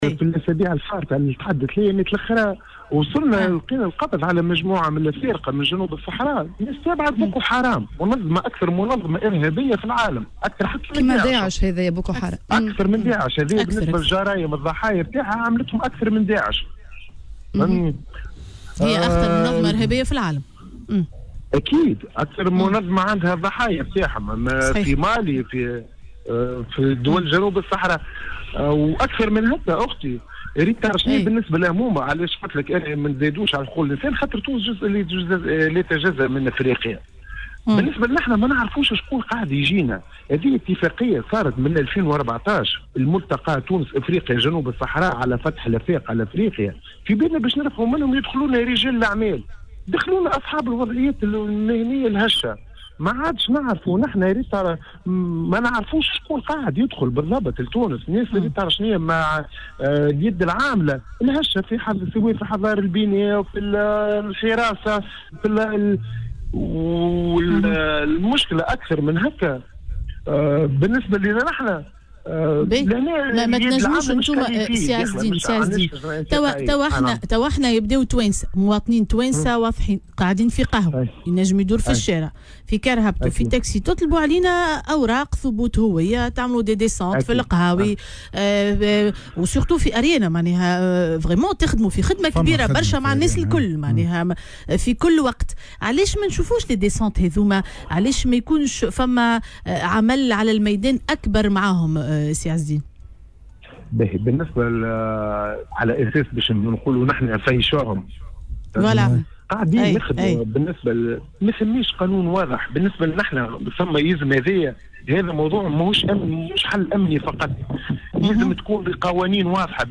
وأضاف في مداخلة له اليوم على "الجوهرة أف أم" أنه لابد من إعادة النظر في ملف المهاجرين والتثبت من هوياتهم وتشديد الإجراءات الرقابية على الحدود. وتابع أن الحل الأمني ليس حلاّ، وأنه يجب إعادة النظر في القوانين ( أغلبهم يملكون وثائق إقامة وقتية).